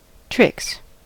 tricks: Wikimedia Commons US English Pronunciations
En-us-tricks.WAV